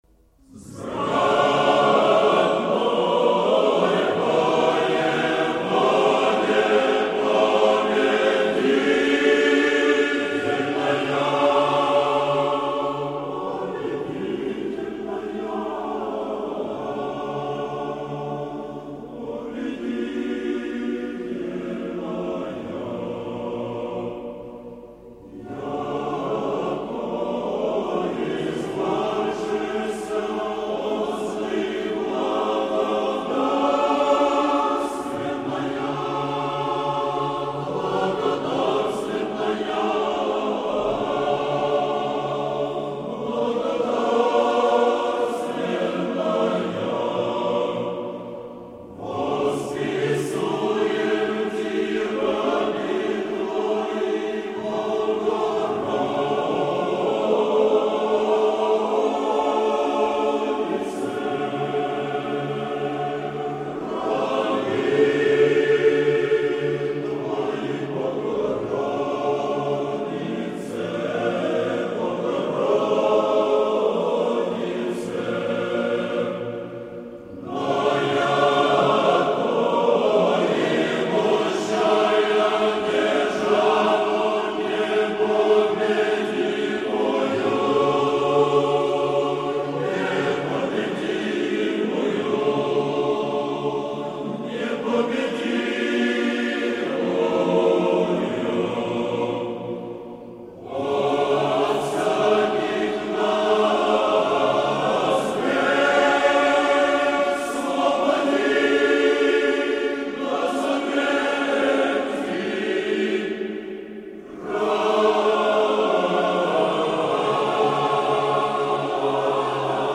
Объединенный хор Троице-Сергиевой Лавры и МДА. "Взбранной Воеводе победительная"
hor-sergievoj-lavry-vzbrannoj-voevode.mp3